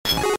Cri de Rondoudou K.O. dans Pokémon Diamant et Perle.